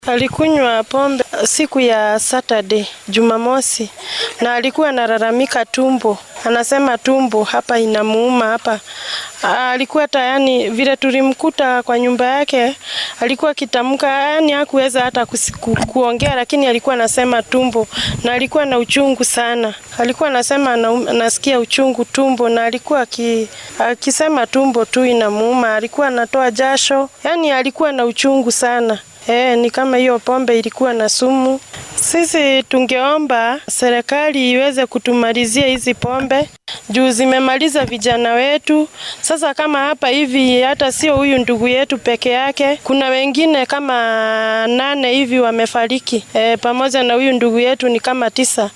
Mid ka mid ehellada dadkii ku nafwaayay cabidda Khamriga ayaa la hadashay warbaahinta.